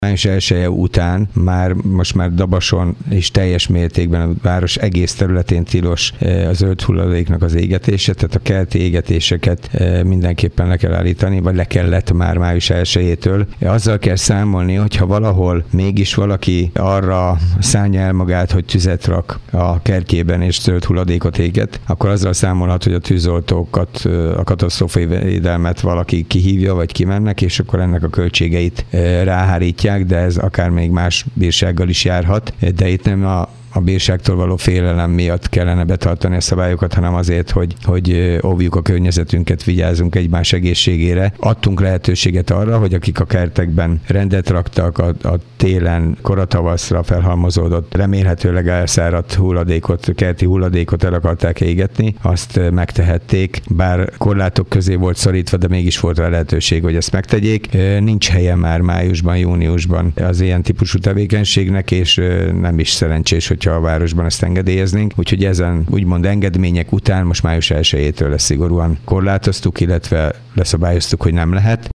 Tavasszal meghatározott napokon engedélyezték a zöldhulladék égetését, azonban május 1-je óta a város egész területén tilos az égetés. Kőszegi Zoltán polgármestert hallják.